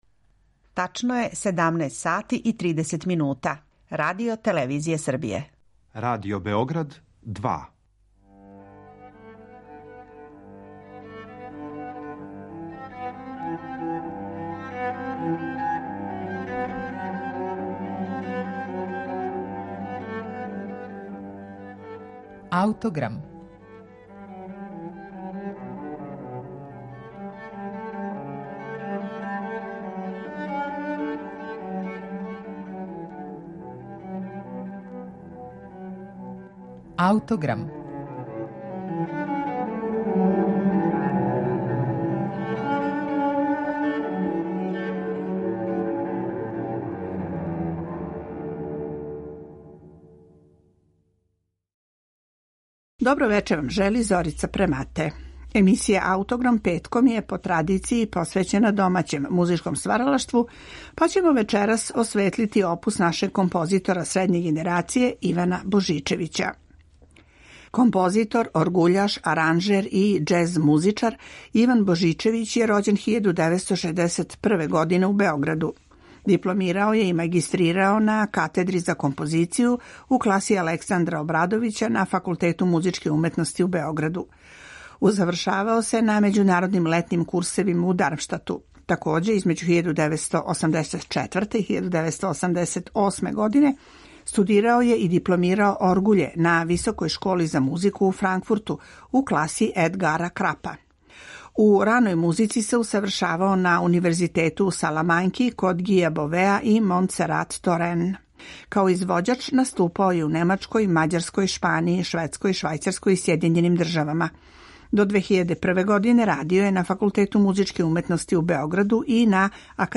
Овога пута слушаћете музику за клавир нашег композитора